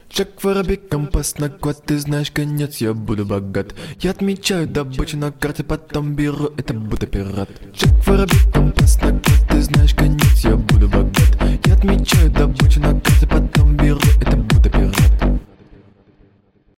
• Качество: 128, Stereo
мужской голос
забавные
Electronic
качающие